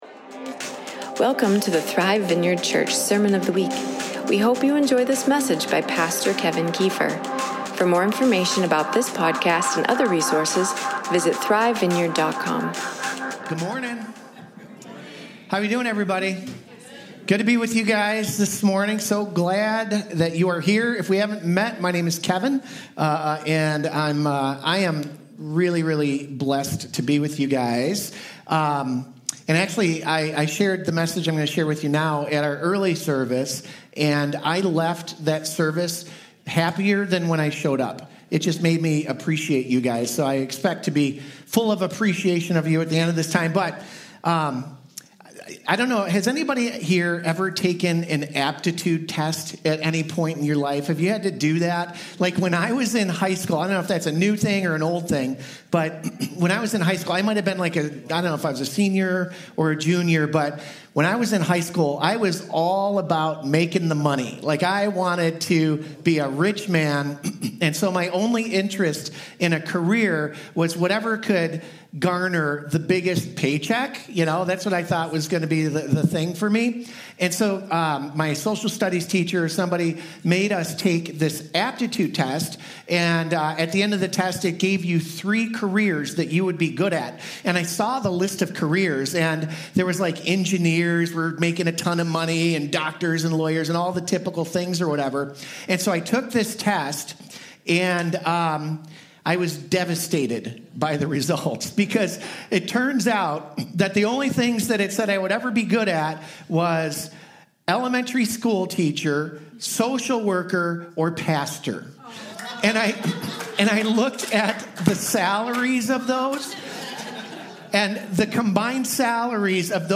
2025 This is Church Sunday Service What if the missing piece in the church isn’t something—but someone?